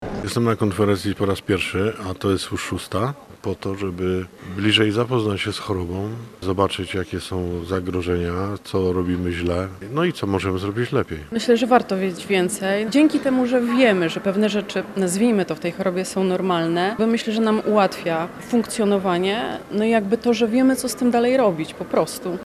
Jak mówią pacjenci takie spotkania są potrzebna i dla chorych ich rodzin a także lekarzy: